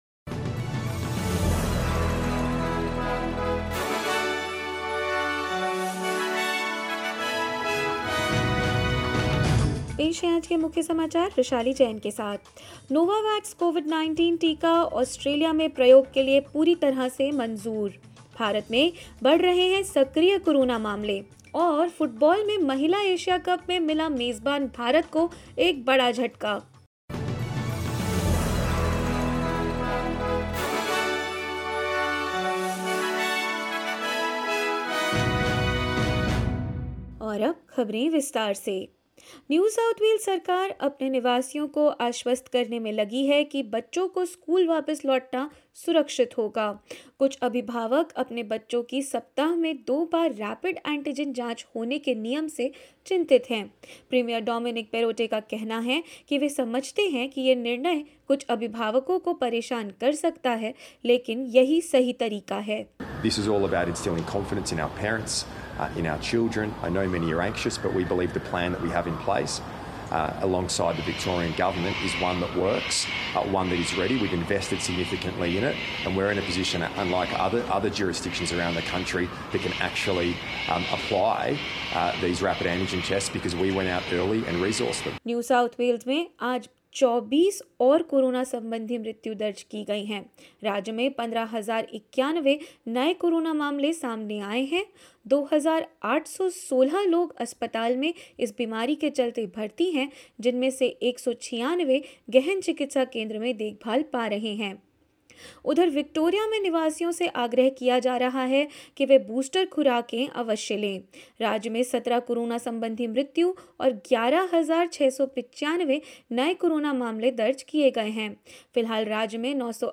SBS Hindi News 24 January 2022: Australia approves Novavax COVID-19 vaccine for use in country